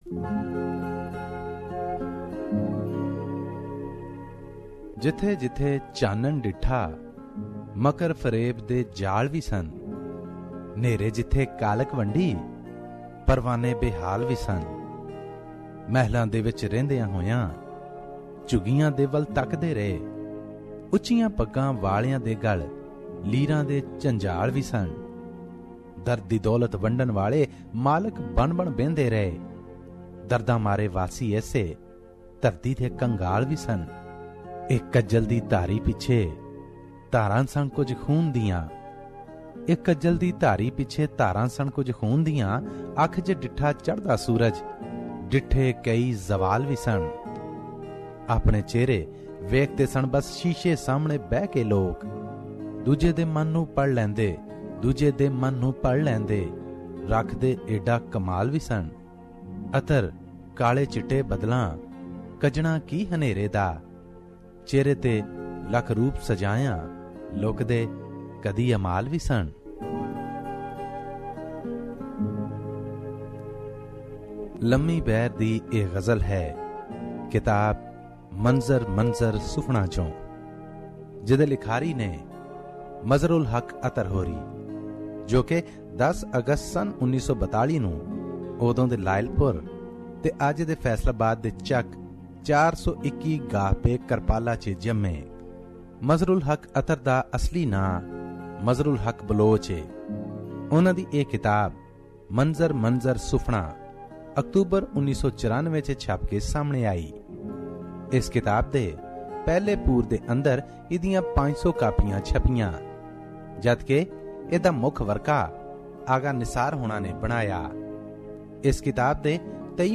Here is another Punjabi book review